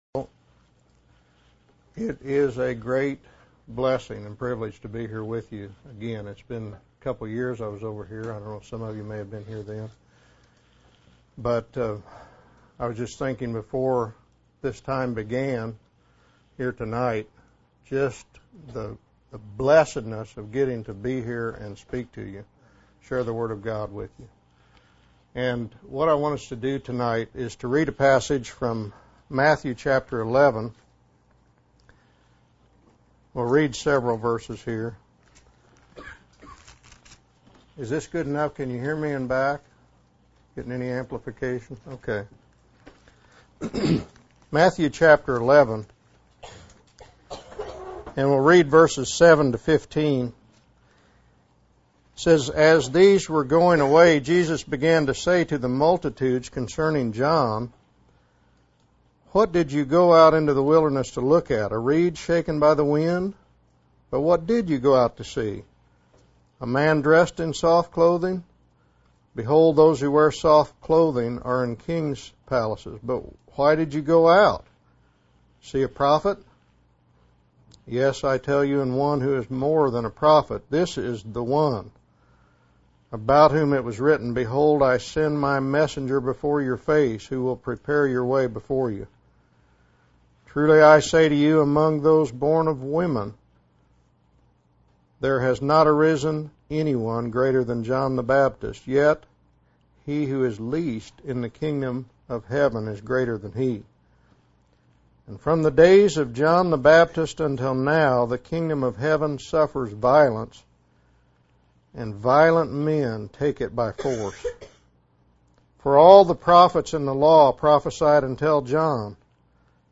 In this sermon, the preacher emphasizes the importance of being violent towards sin and having a strong desire to know God. He references Jesus' words about throwing away anything that causes us to sin, even if it goes against our theology.